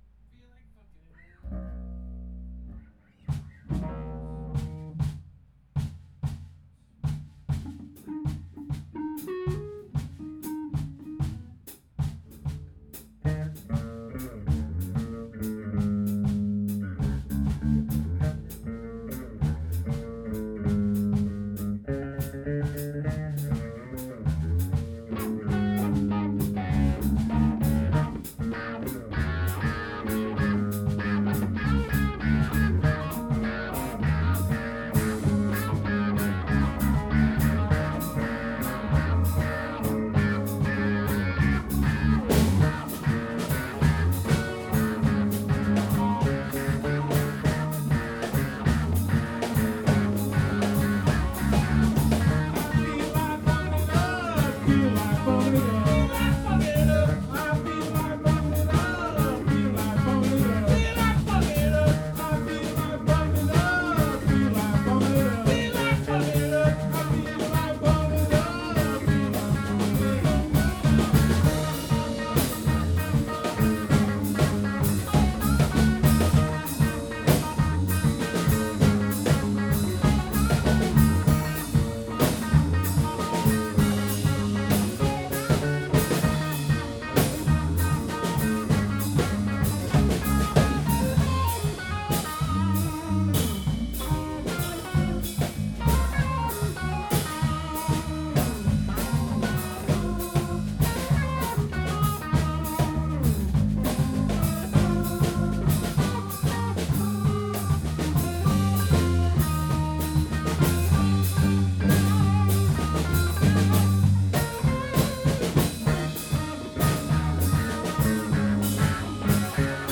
Ebm